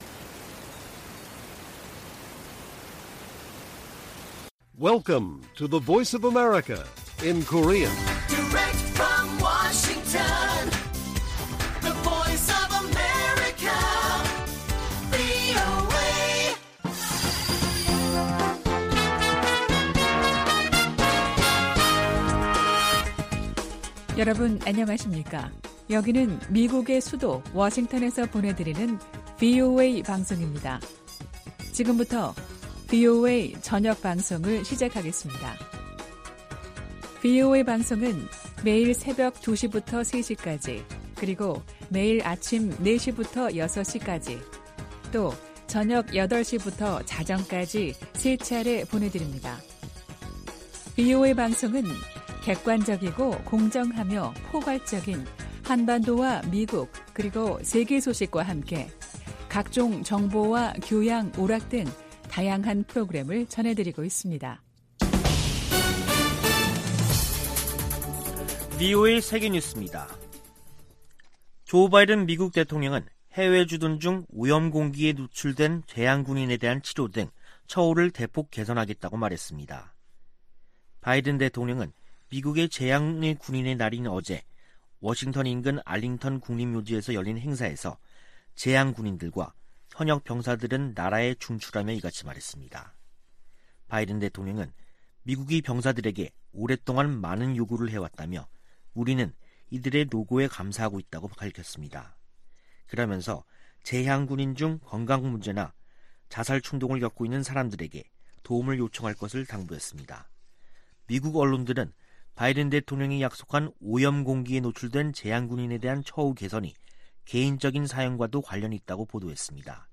VOA 한국어 간판 뉴스 프로그램 '뉴스 투데이', 2021년 11월 12일 1부 방송입니다. 미국과 종전선언의 큰 원칙에 합의했다는 한국 외교부 장관의 발언이 나오면서 실제 성사 여부에 관심이 쏠리고 있습니다. 북한이 핵실험을 유예하고 있지만 미사일 탐지 회피 역량 개발에 집중하고 있다고 미국 유력 신문이 보도했습니다. 미국 정부가 캄보디아와 연관된 미국 기업들에 대한 주의보를 발령하면서, 북한의 현지 불법 활동에 대해서도 주의를 당부했습니다.